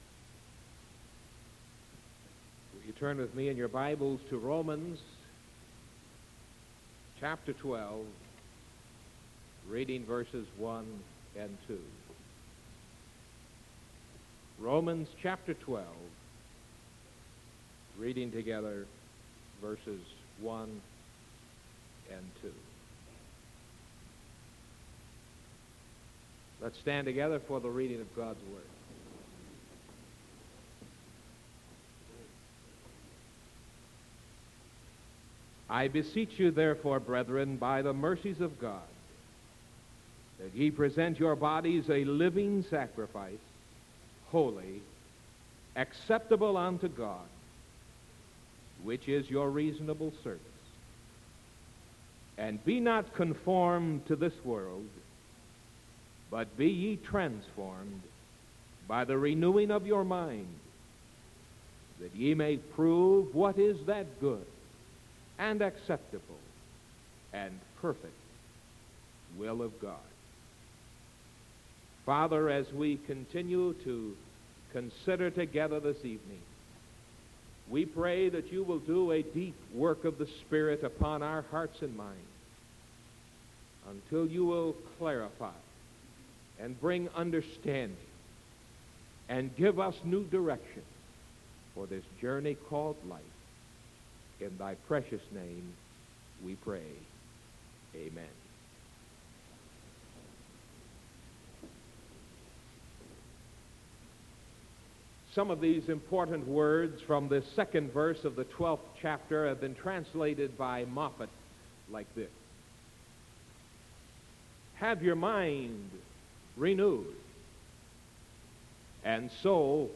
Sermon May 5th 1974 PM